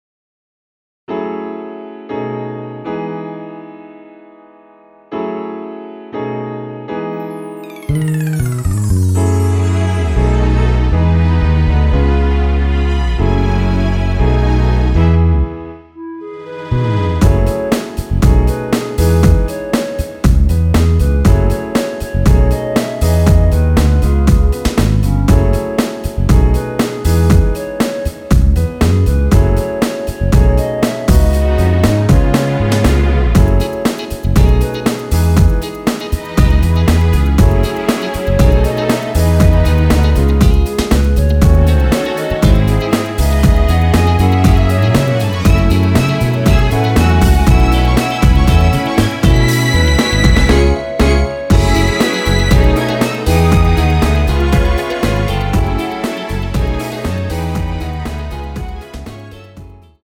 원키에서(+2)올린 멜로디 포함된 MR입니다.(미리듣기 확인)
앞부분30초, 뒷부분30초씩 편집해서 올려 드리고 있습니다.
(멜로디 MR)은 가이드 멜로디가 포함된 MR 입니다.